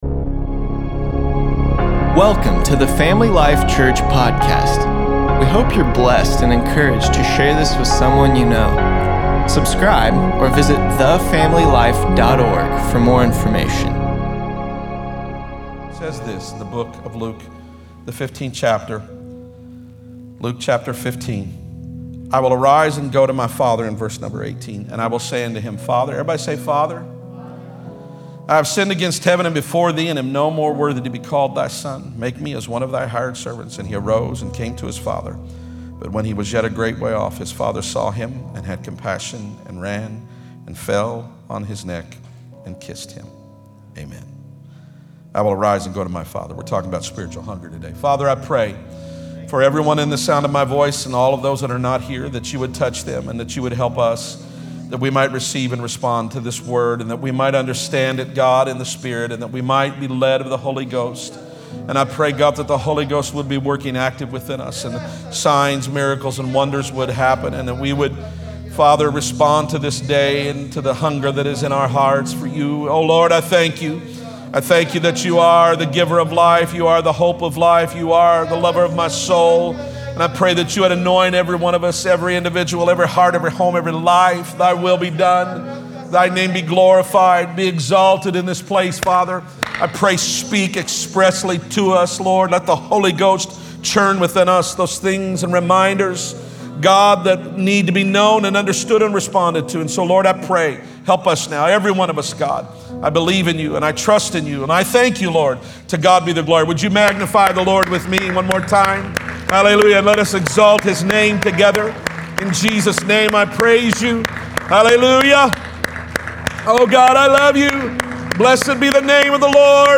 7.5.20_sermon_p.mp3